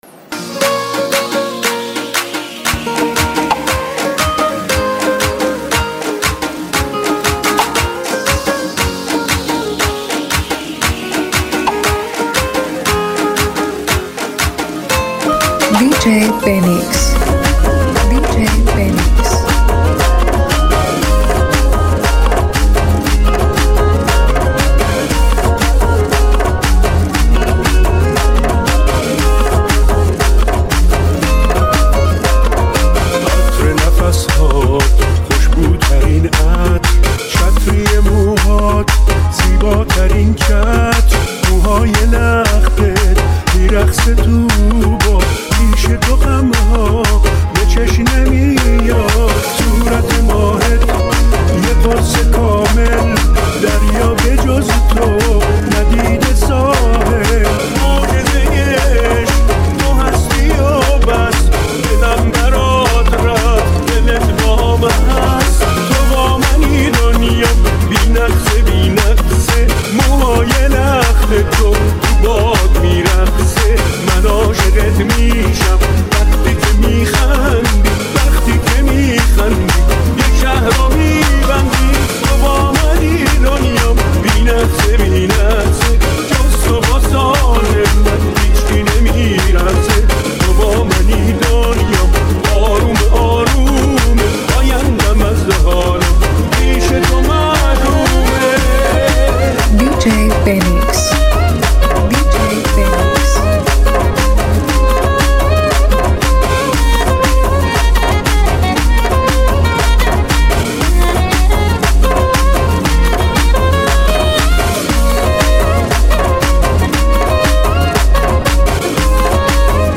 ریمیکس پرانرژی
ضرب‌آهنگ‌های مدرن